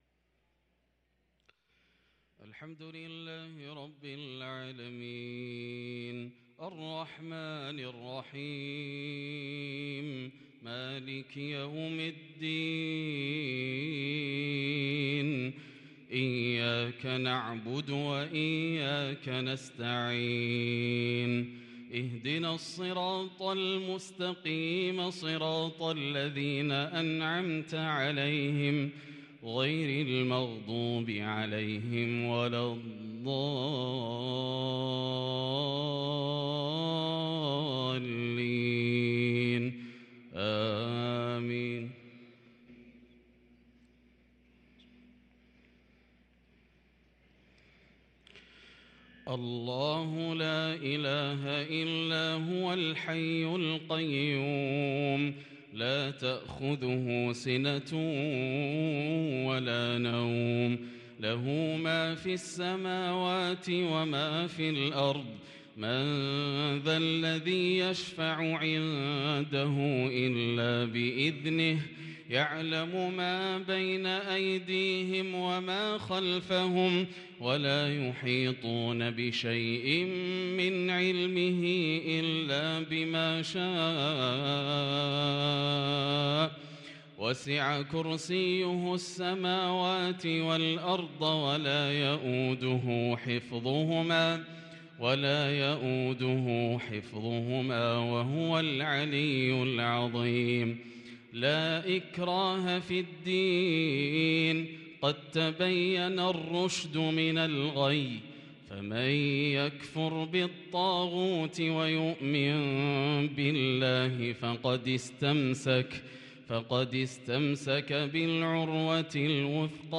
صلاة العشاء للقارئ ياسر الدوسري 11 ربيع الآخر 1444 هـ
تِلَاوَات الْحَرَمَيْن .